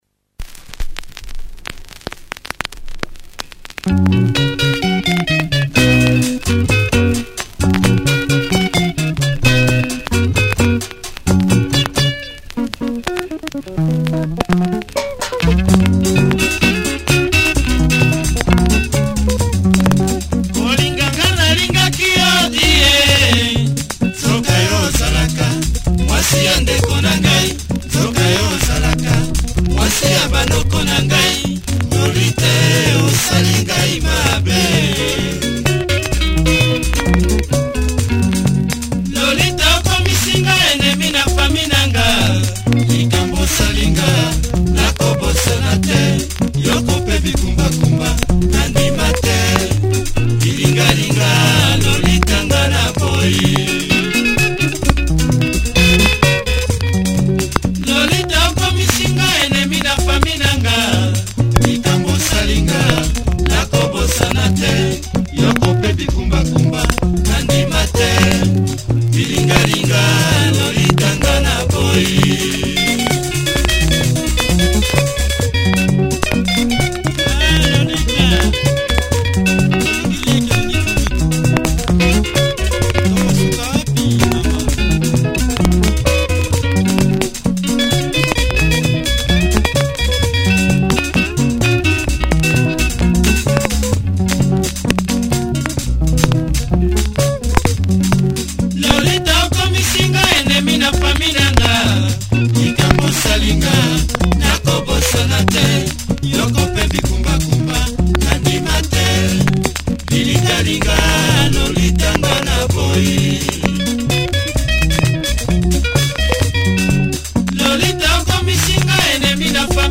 Clean copy better than the one in the soundclip!